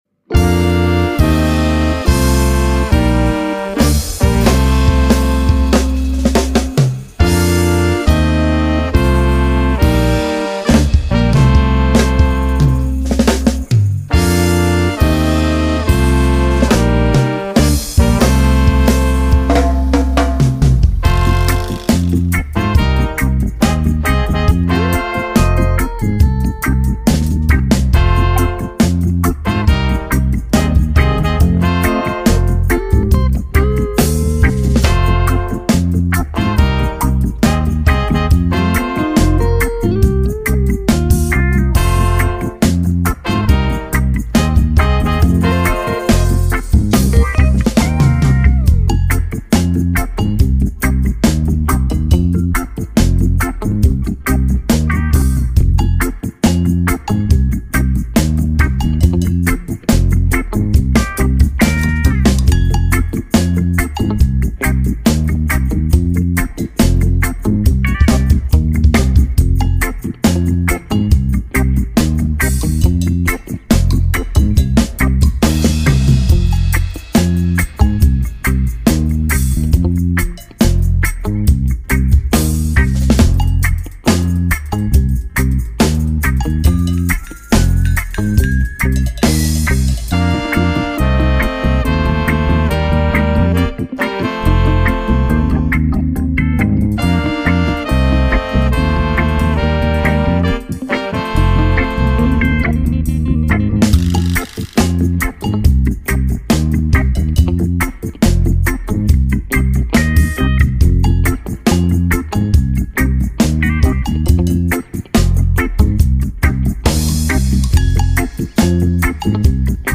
Drum
Bass
Organ
Sax